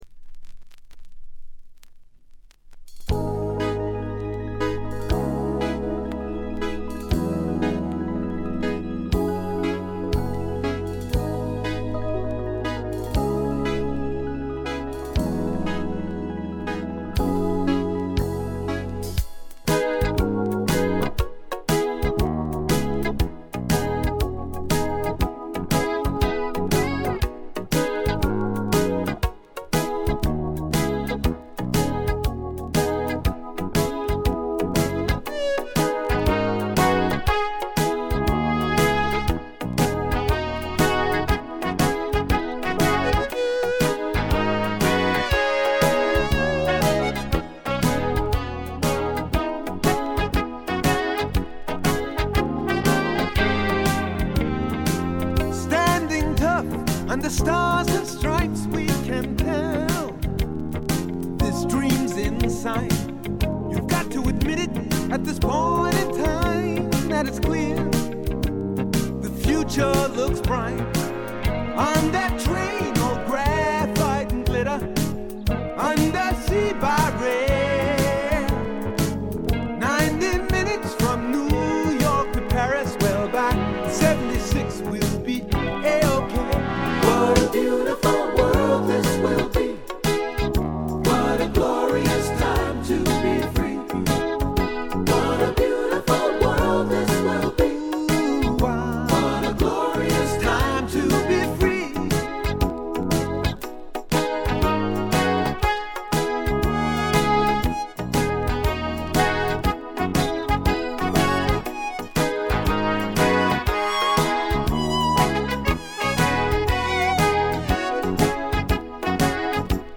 チリプチ少々（特にA面曲が始まる前の無音部）。
試聴曲は現品からの取り込み音源です。